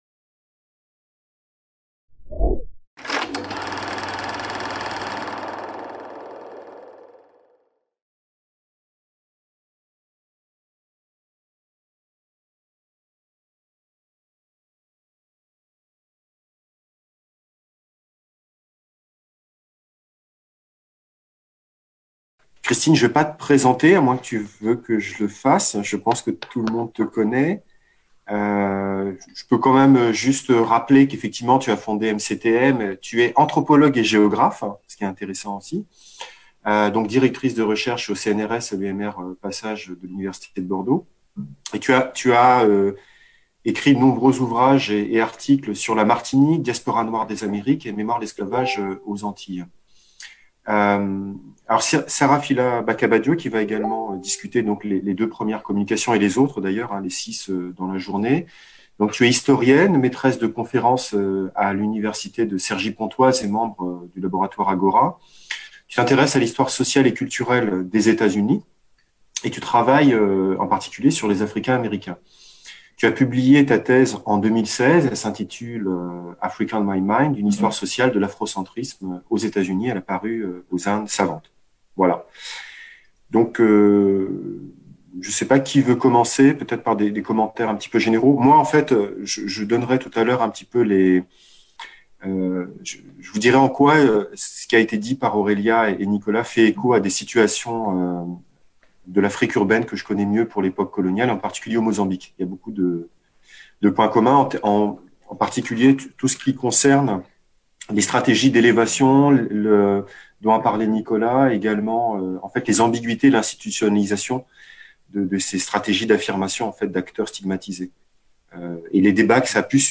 Corps et performances de dignité en contexte (post-)colonial. Amériques, Afrique, Océan indien - Discussion 1 | Canal U